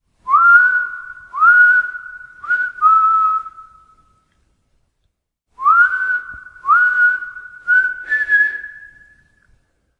Звуки дикого запада
Звук со свистом Дикого запада